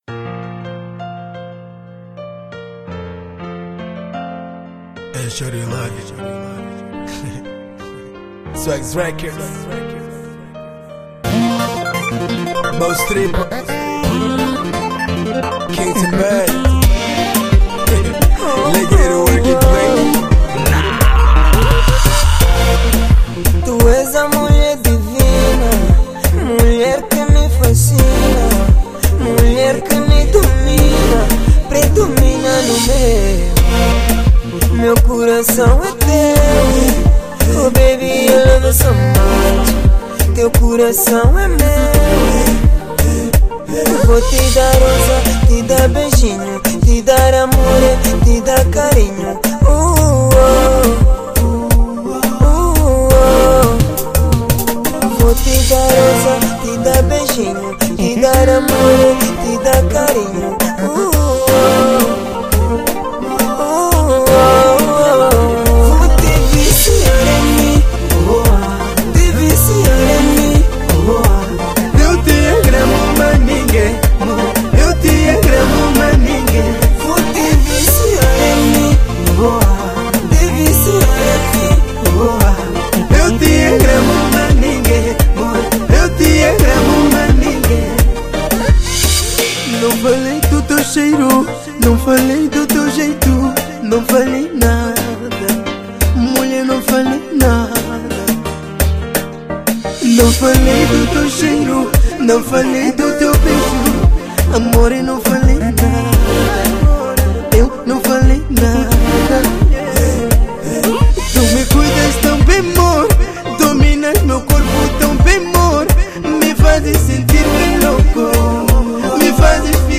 Zouk